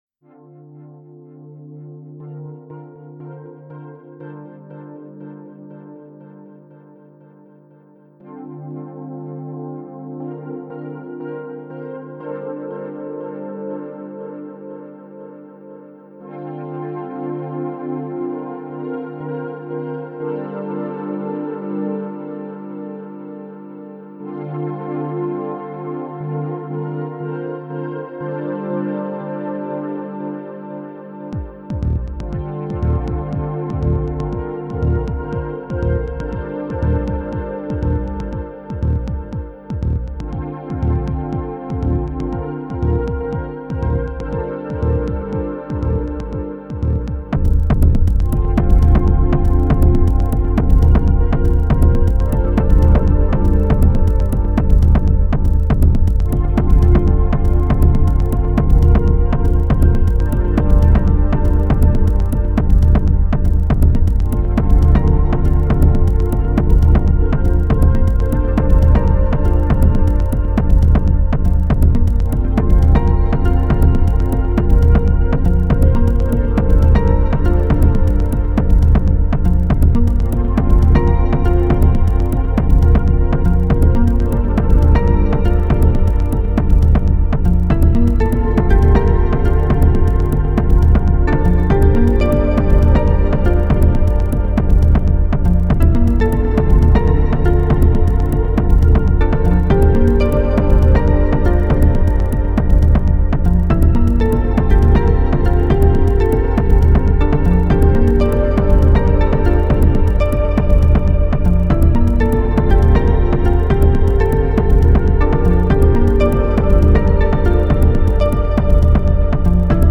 Genre: Techno Ambient.